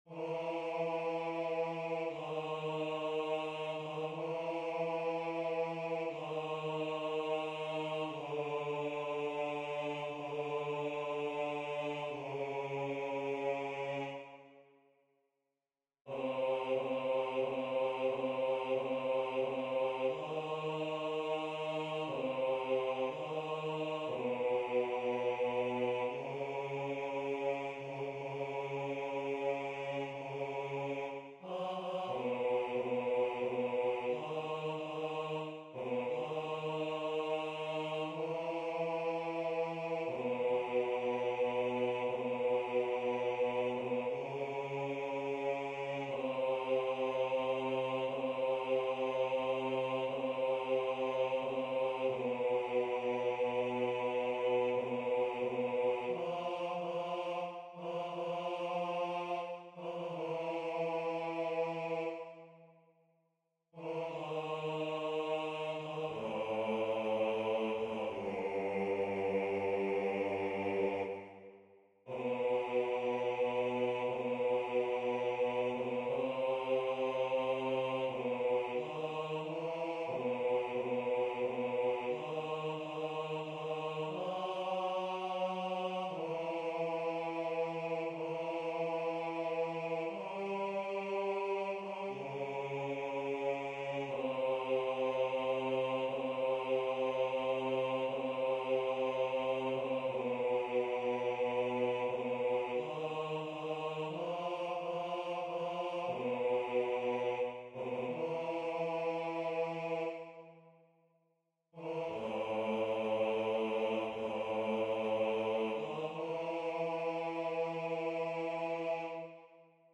MP3 versions rendu voix synth.
Hommes